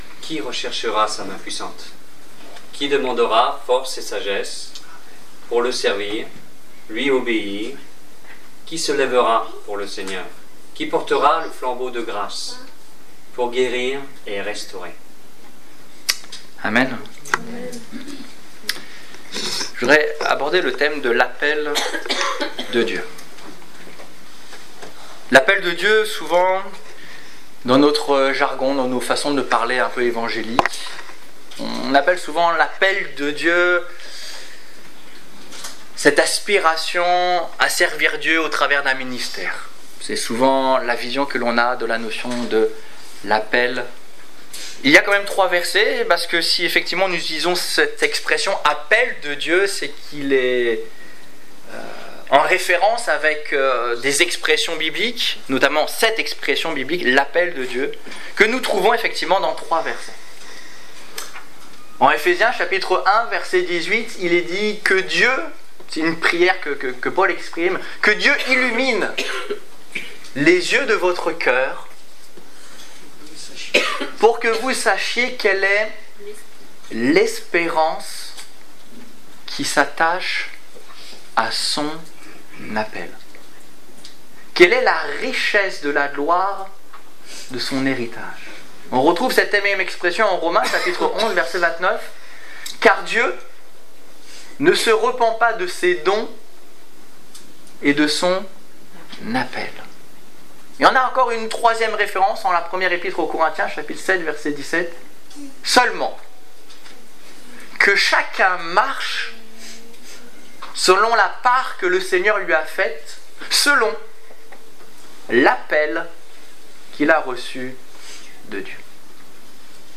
L'appel de Dieu (1/2) Détails Prédications - liste complète Culte du 29 novembre 2015 Ecoutez l'enregistrement de ce message à l'aide du lecteur